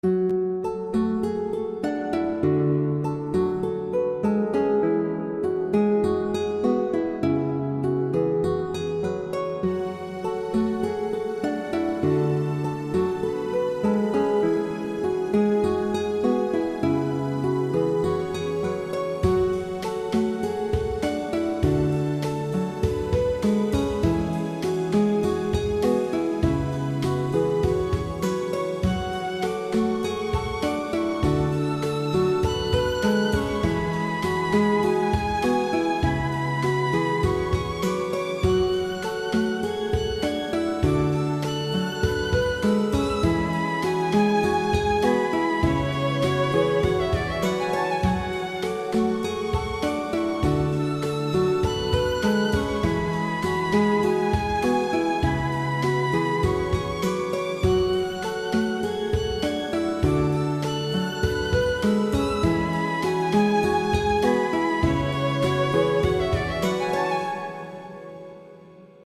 :) Gypsy'i ilk aldığım zamanlar onun gazıyla klasik gitar manyağı olmuştum :) Buyrun bakalım :